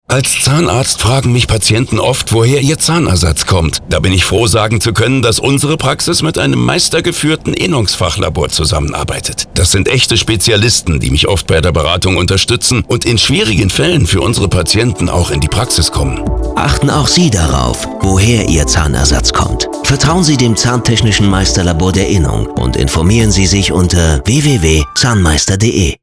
In Kürze im Radio (WDR2) auf Sendung - neuer Funkspot der Innungslaboratorien in NRW